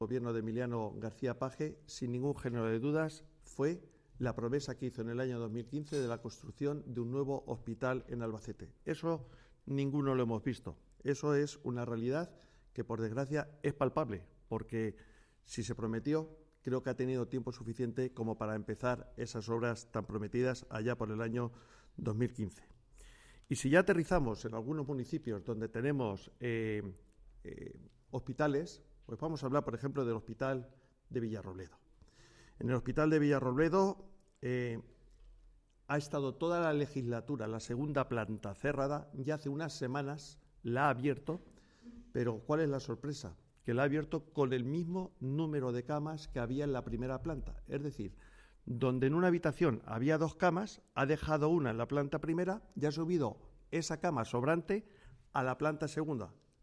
Desayuno informativo de la candidatura del Partido Popular a las Cortes de Castilla-La Mancha
en el transcurso de un desayuno con medios informativos de Albacete, Vicente Aroca criticó que Page haya vuelto a las promesas de 2015, cuando anunció la construcción de un nuevo Hospital para Albacete